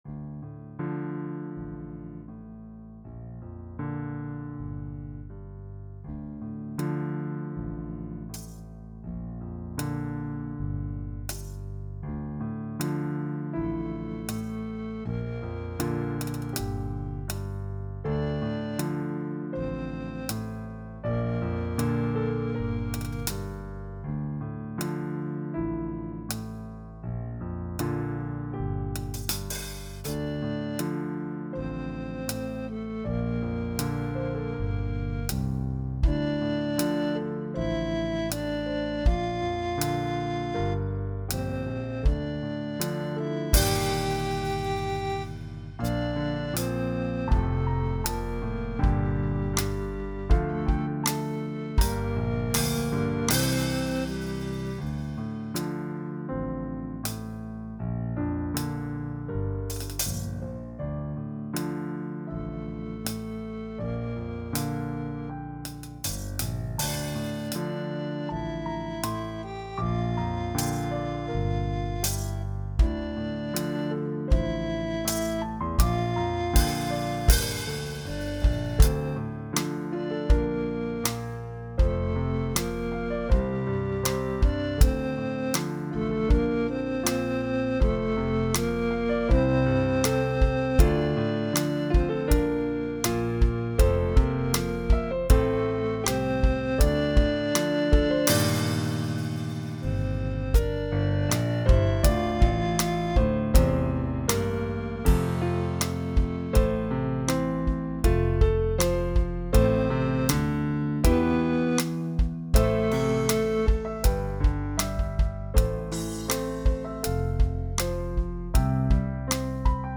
• Elegy (instrumental)